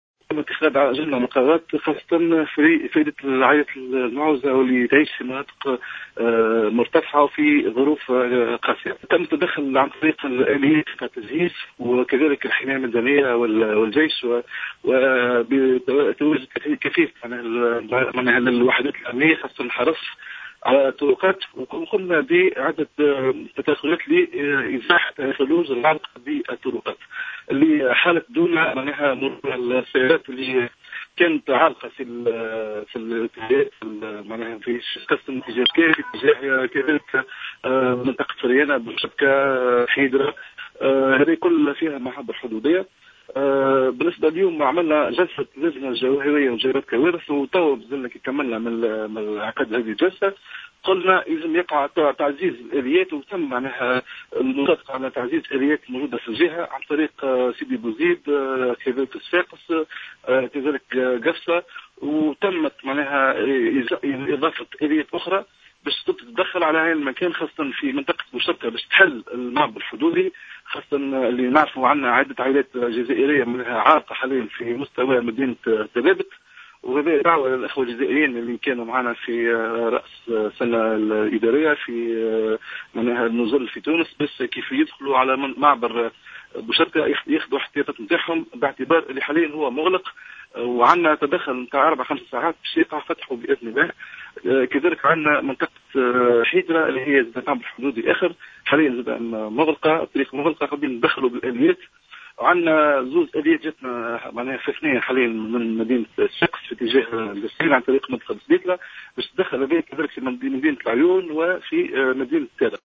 أفاد والي القصرين ورئيسُ اللجنة الجهوية لمجابهة الكوارث الطبيعية عاطف بوغطاس، في اتصال هاتفي لجوْهرة أف أم مساء اليوم الخميس بأنّه تم التدخّل عبر الجرّافات في عدد من المناطق لفتح الطرقات والمسالك وفك عُزلتها و أكدّ أنّ المعابر الحدودية مع الجزائر مُغلقة حاليا بسبب الثلوج.